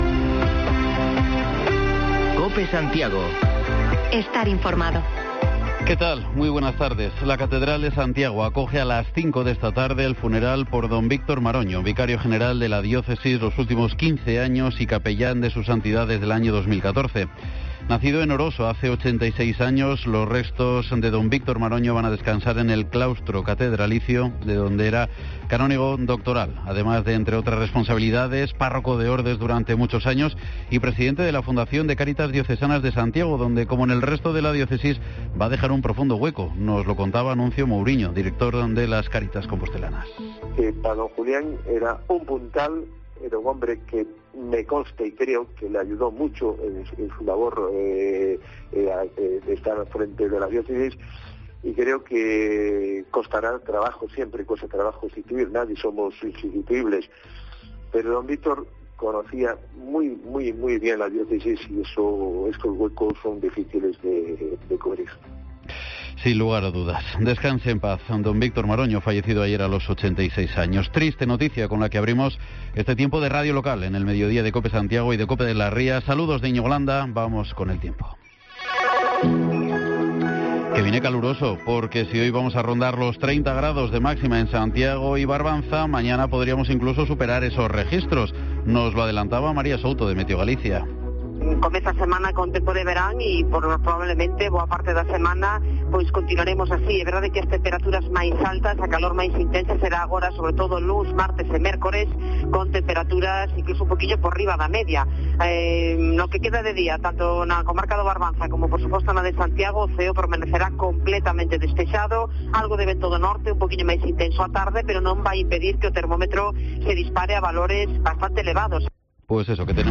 Podcast: Informativo local Mediodía en Cope Santiago y de las Rías 23/08/2021